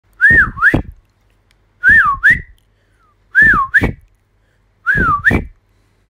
口笛 着信音
効果音 口笛MP3